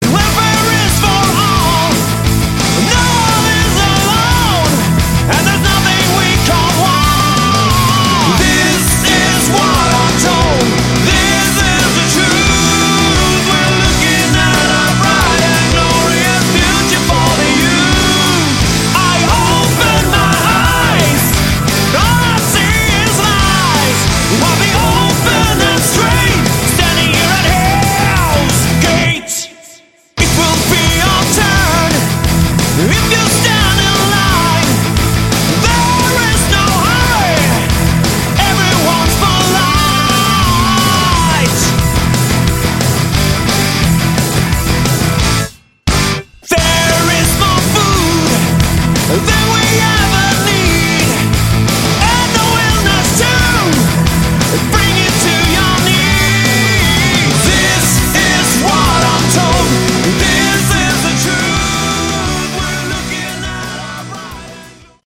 Category: Hard Rock
Guitars
Keyboards
Bass
Drums
Vocals